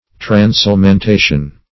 Search Result for " transelementation" : The Collaborative International Dictionary of English v.0.48: Transelementation \Trans*el`e*men*ta"tion\, n. [Cf. F. trans['e]l['e]mentation.]